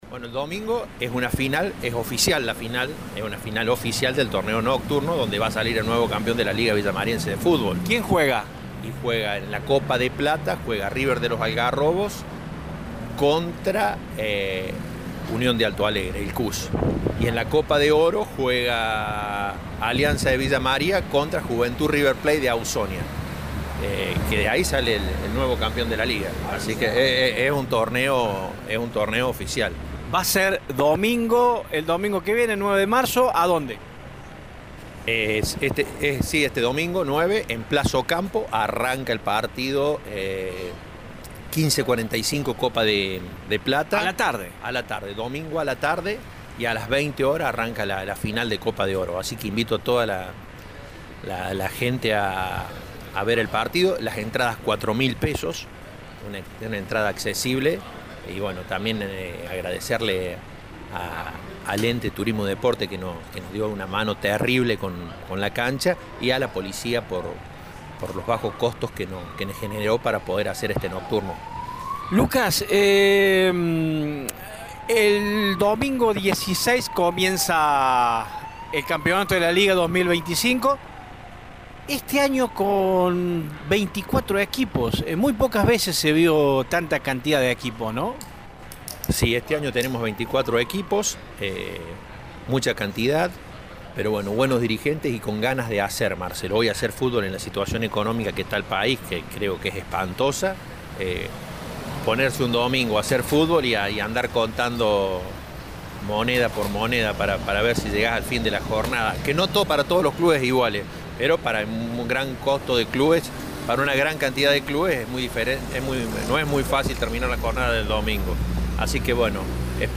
en diálogo con Radio Show brindó detalles sobre el evento.